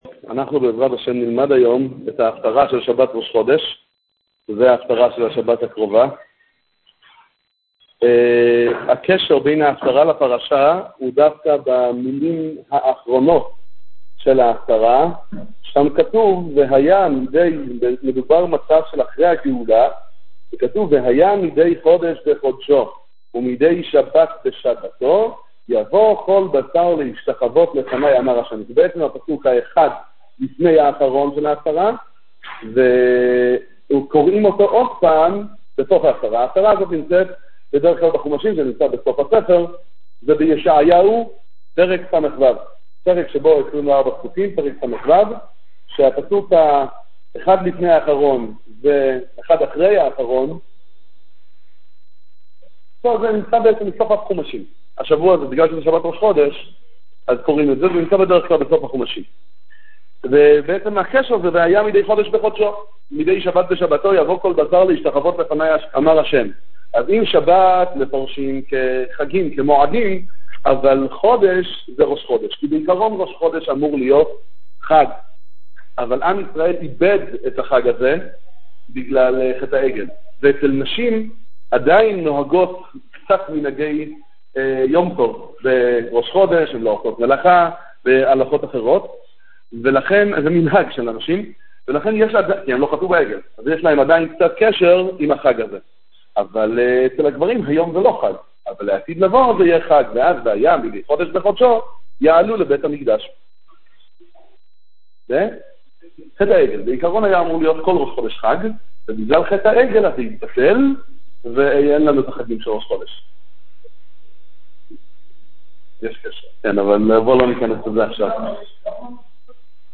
שיעור מביהמ"ד משאת מרדכי רמב"ש א'